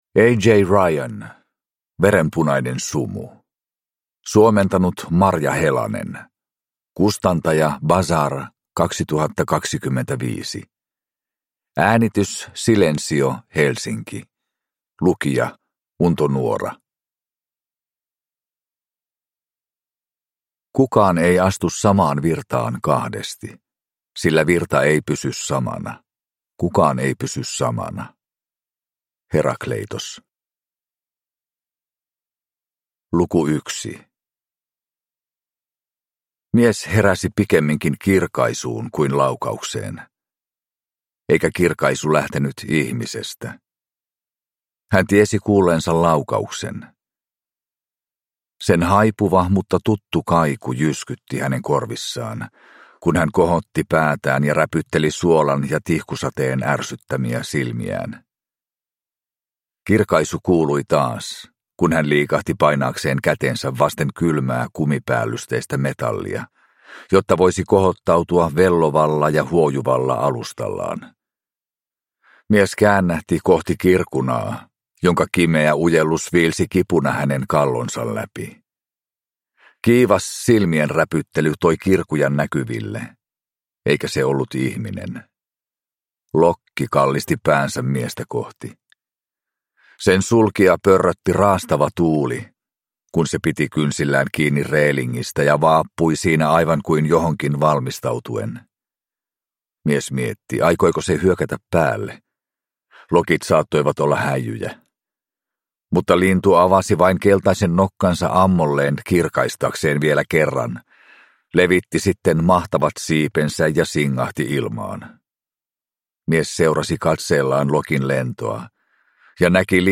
Verenpunainen sumu – Ljudbok